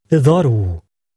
A collection of useful phrases in European Portuguese, the type of Portuguese spoken in Portugal.